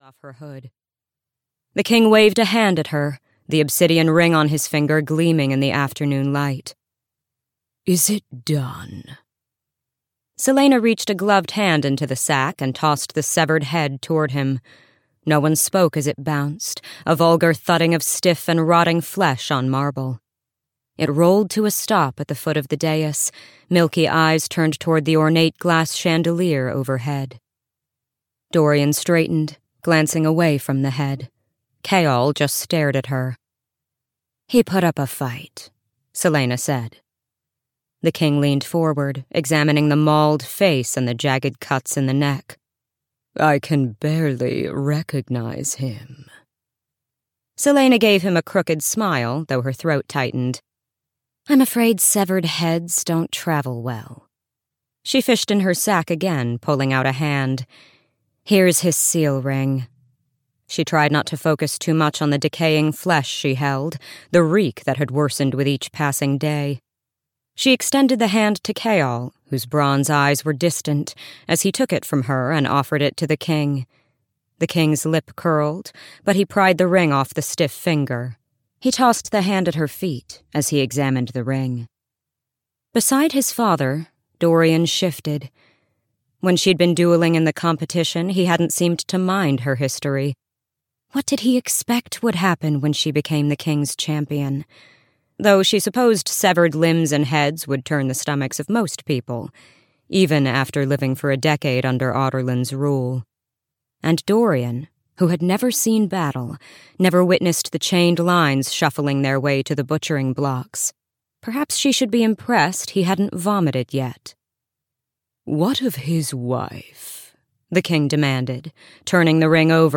Crown of Midnight (EN) audiokniha
Ukázka z knihy